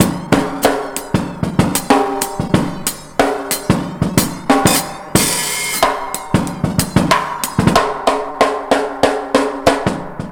Index of /90_sSampleCDs/Best Service ProSamples vol.24 - Breakbeat [AKAI] 1CD/Partition B/ONE HAND 093